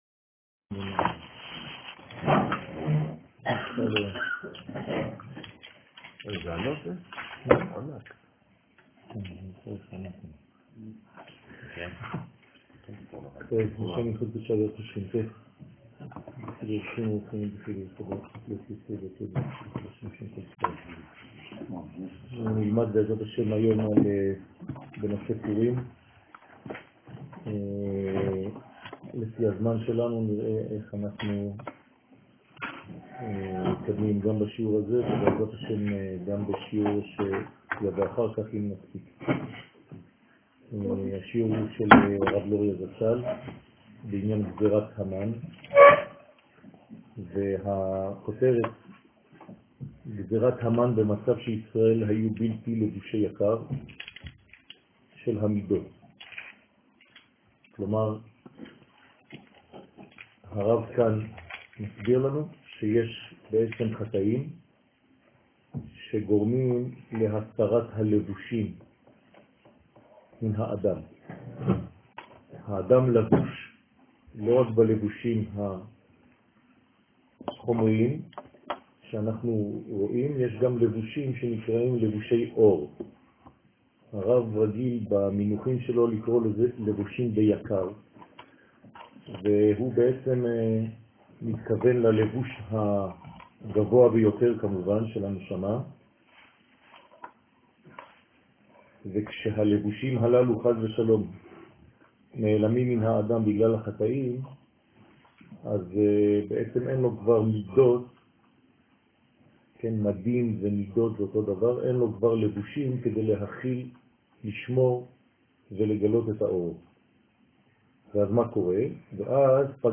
שיעור לילה : יום הכיפורים